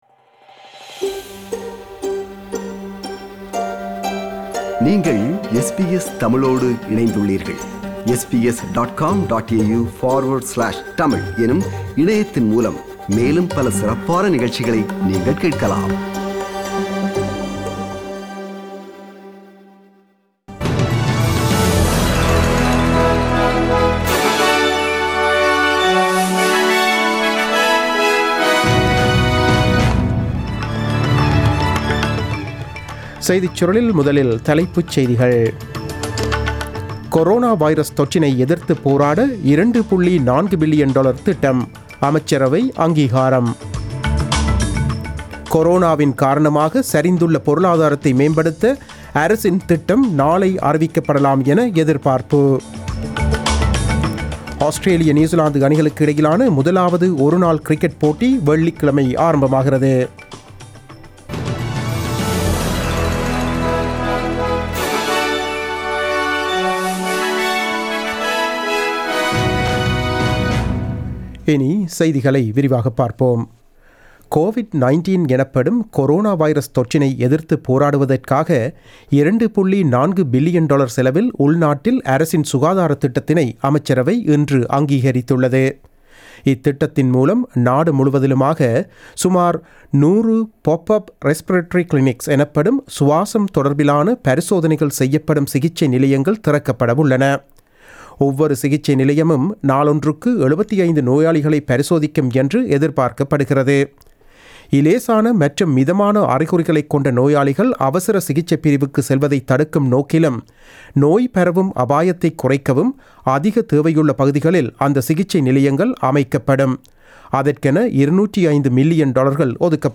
நமது SBS தமிழ் ஒலிபரப்பில் இன்று புதன்கிழமை (11 March 2020) இரவு 8 மணிக்கு ஒலித்த ஆஸ்திரேலியா குறித்த செய்திகள்.